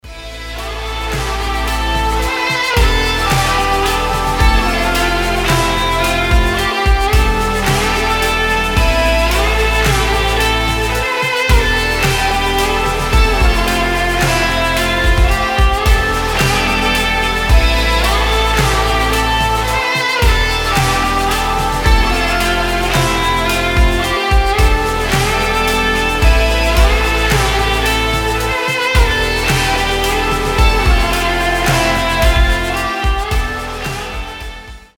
без слов
восточные
турецкие